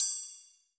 FX CYMB 2.WAV